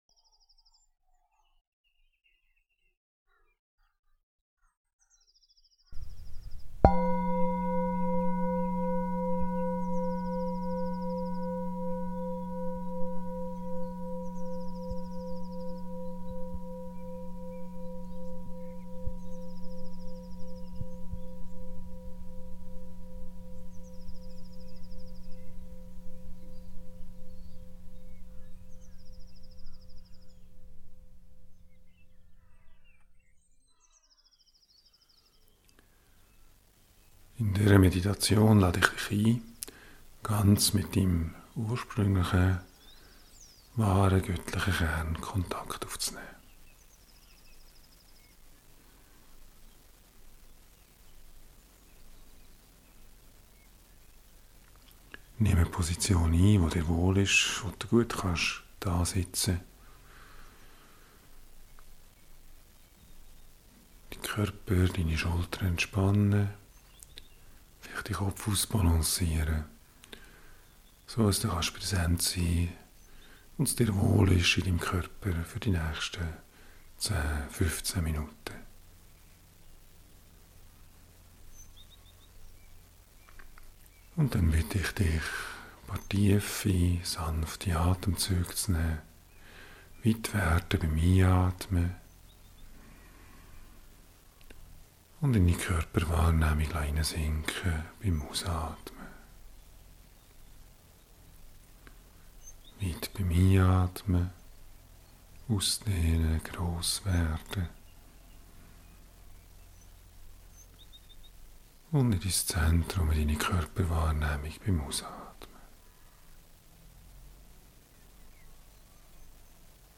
horprobeausbasismeditation1.mp3